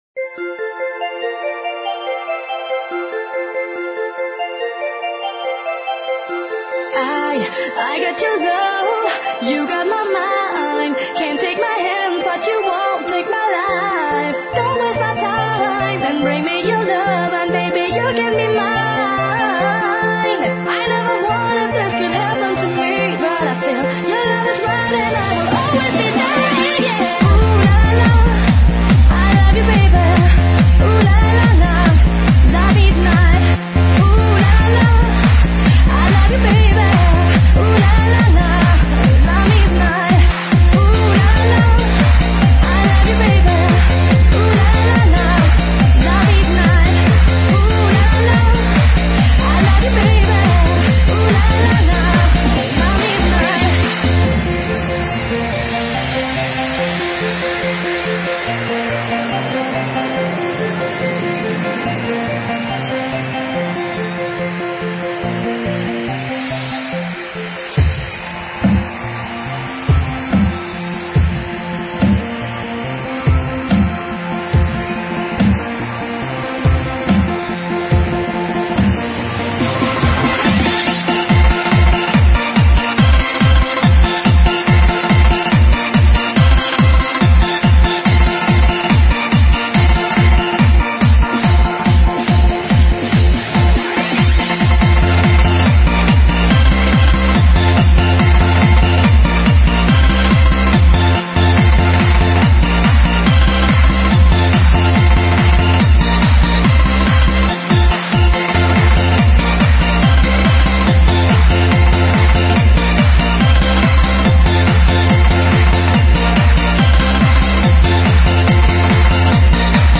Жанр:Super/Club/Dance